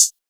Metro CL Hat 2.wav